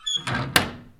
default_use_furnace.ogg